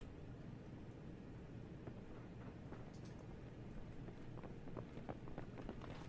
Quiet Footsteps
quiet-footsteps.mp3